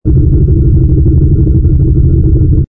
rumble_ci_h_fighter.wav